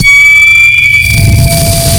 sonarPingWaterClose2.ogg